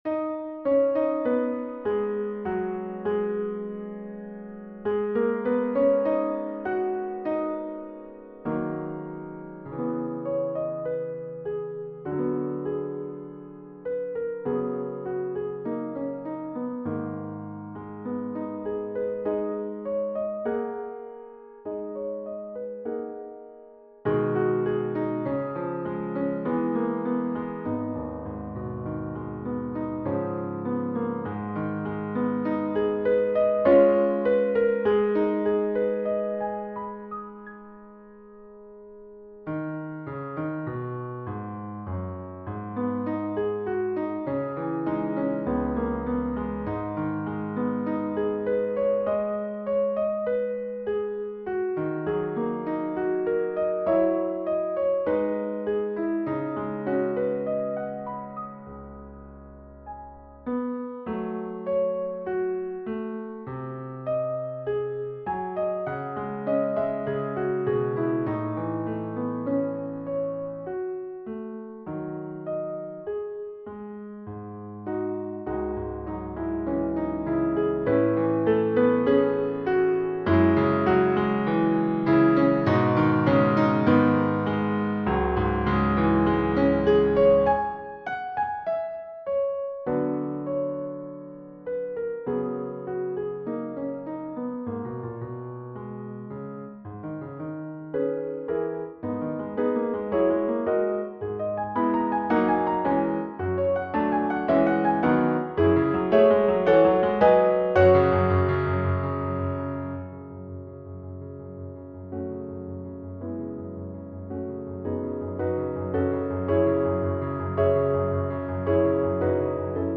This is a digital rendering using MuseScore3.